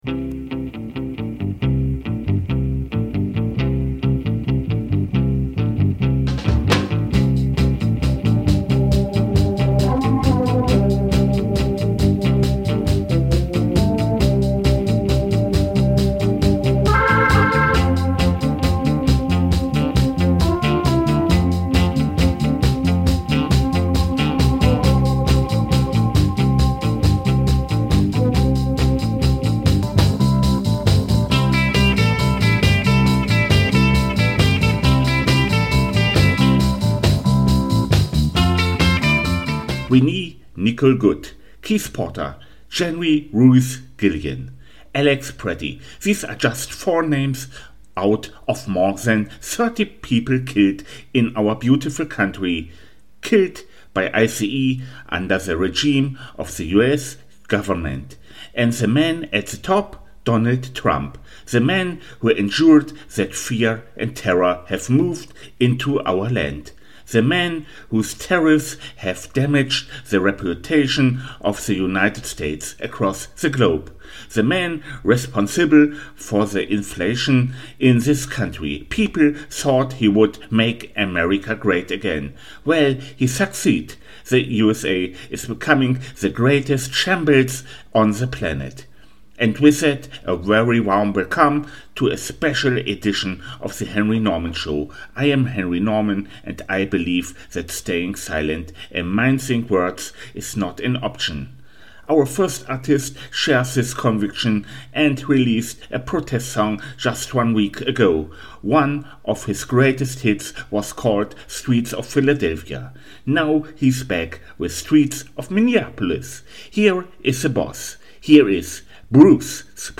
Protestsongs